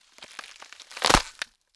BREAK_Slow_stereo.wav